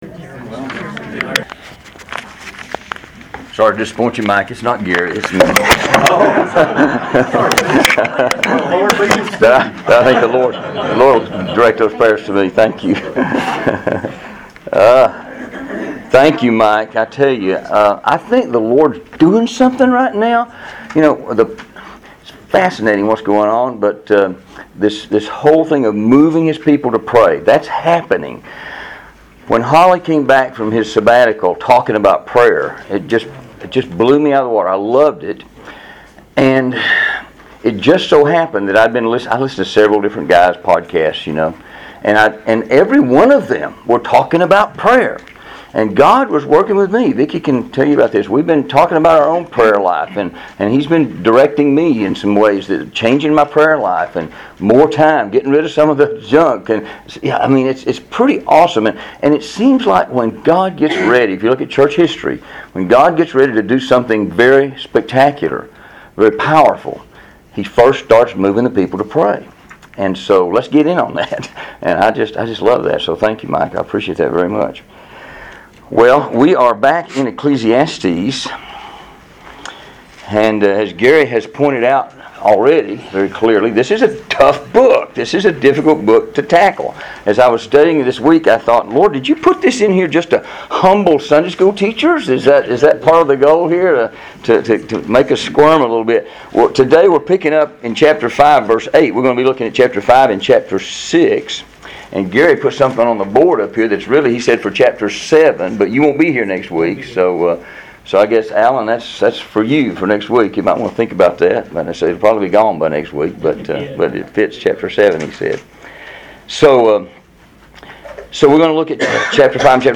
(Recorded live in A5B Bible Fellowship Class at Sevier Heights Baptist Church, Knoxville, TN, on August 4, 2013.)